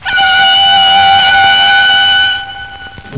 CMアイキャッチ　　444号